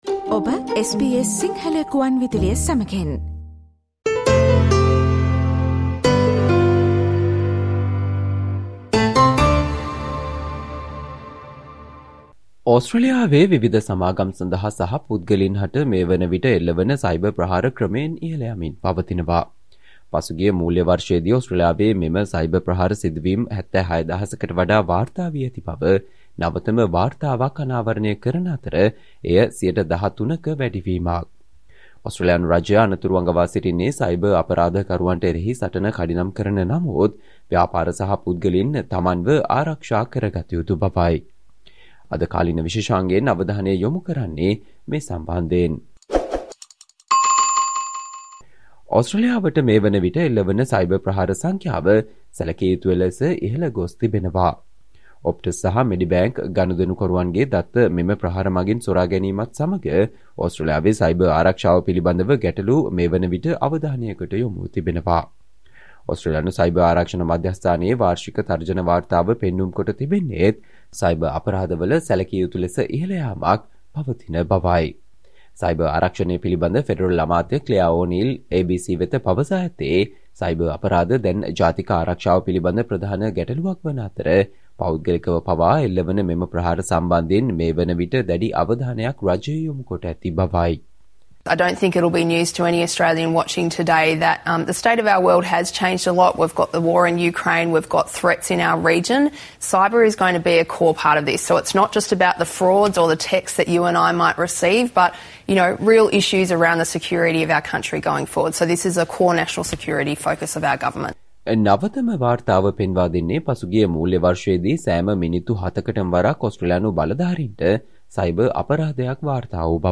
Today - 07 November, SBS Sinhala Radio current Affair Feature on Cybercrime incidents increase 13 per cent